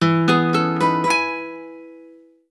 Acoustic_Guitar.ogg